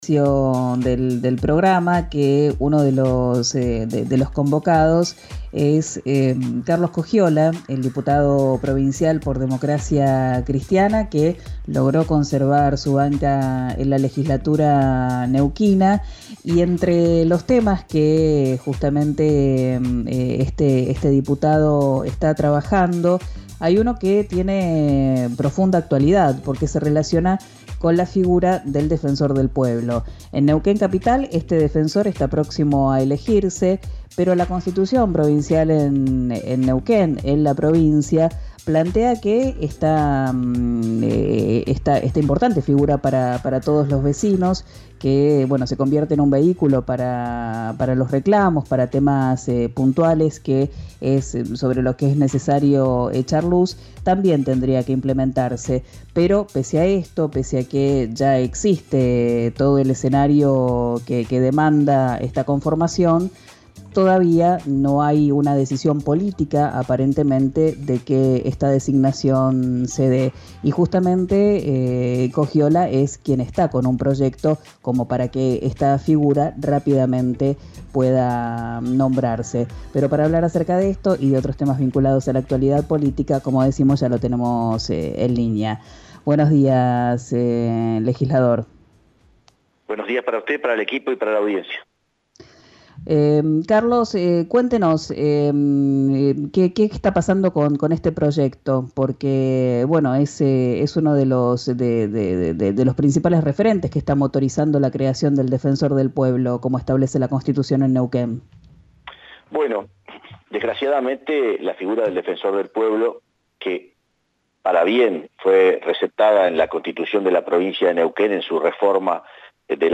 La Constitución Provincial de Neuquén incorporó la figura en la reforma de 1995, pero aún no se reglamenta. El diputado de Neuquén, Carlos Coggiola, habló con RÍO NEGRO RADIO y aseguró que el oficialismo interfiere en el tratamiento del proyecto.